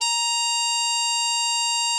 bari_sax_082.wav